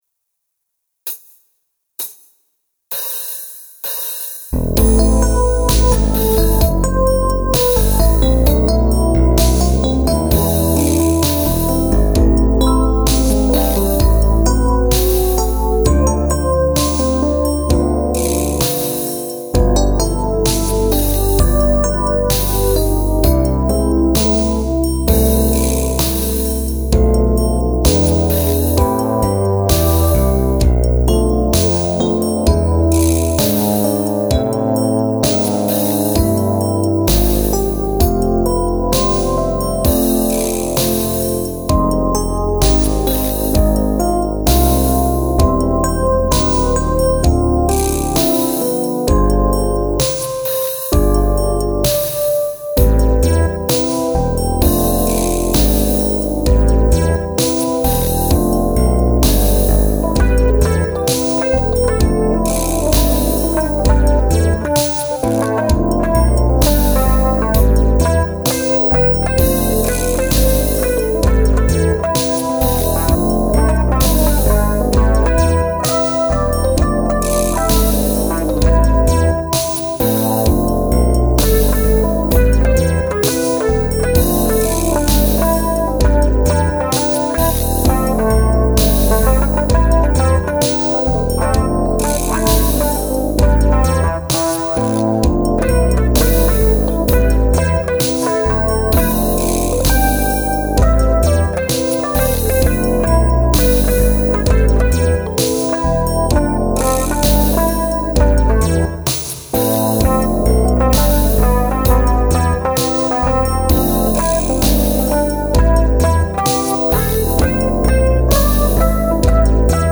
音源は、ＳＣ８８２０を購入したので、さらに良くなっているはず。
テーマに繰り返しがなく、どこまでも展開していくテーマ。
テーマに続くピアノソロは、たしか手弾き。